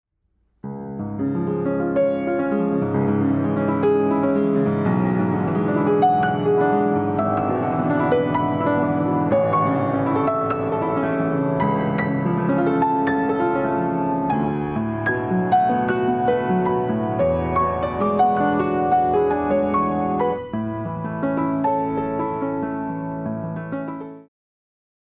グランドピアノと同等のタッチと音を再現したハイエンドモデル。